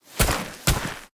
Sfx_creature_snowstalker_turn_01.ogg